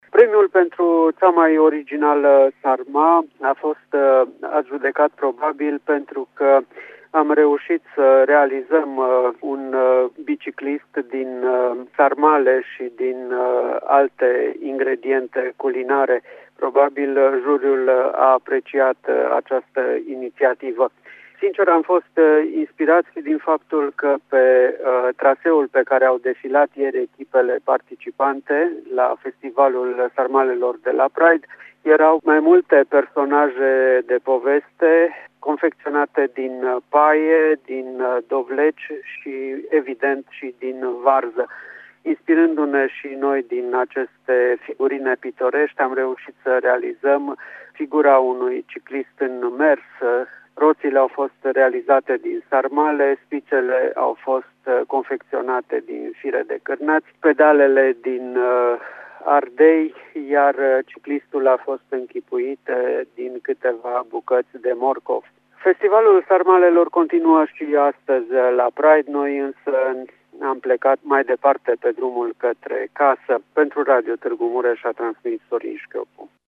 În cadrul concursului de gătit ei au câștigat 2 premii, pentru cea mai originală sarma, respectiv din partea echipelor participante. Aflat pe circuit